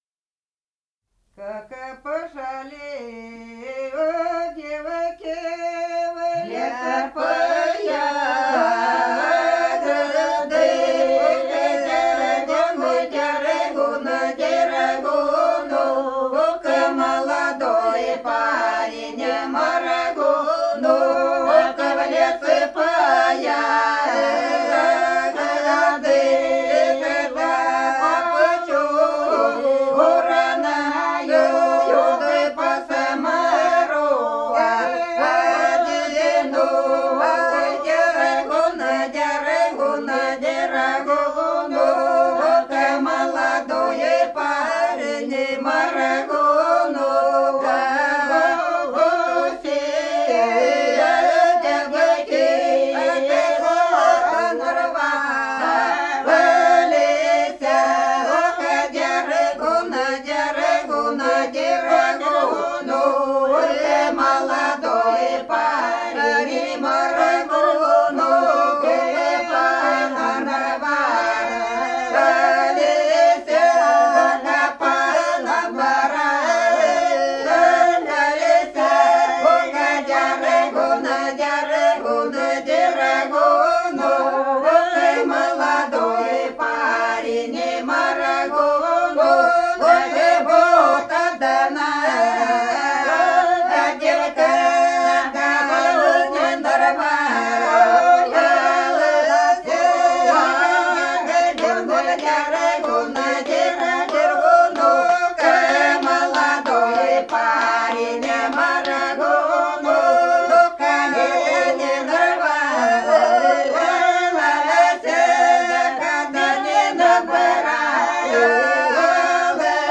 Голоса уходящего века (село Подсереднее) Как пошли девки в лес по ягоды